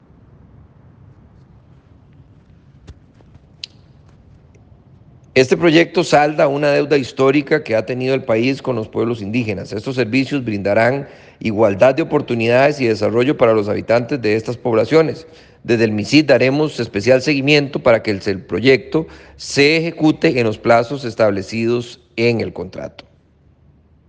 Audio del ministro Luis Adrián Salazar sobre proyecto para llevar Telecomunicaciones a 14 territorios indígenas